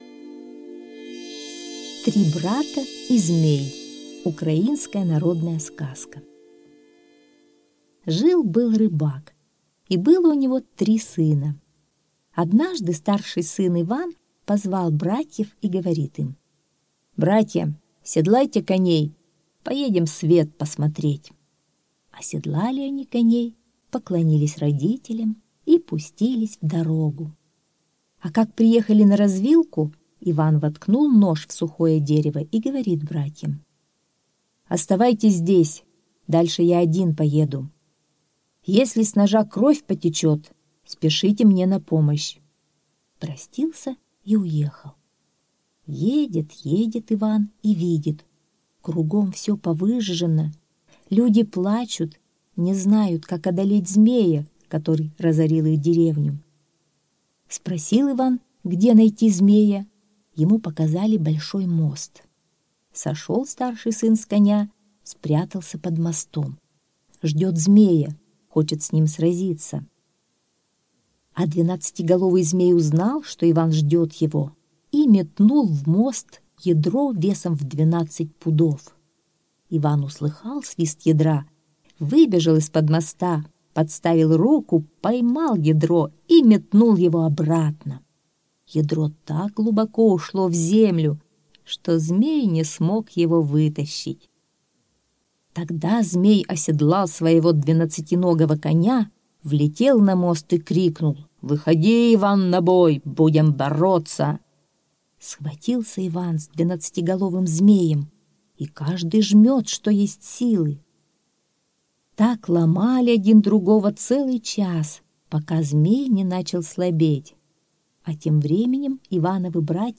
Три брата и змей - украинская аудиосказка - слушать онлайн